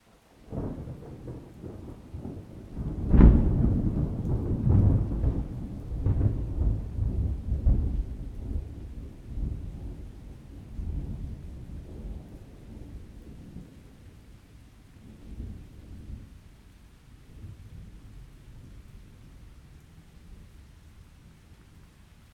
thunder-2.ogg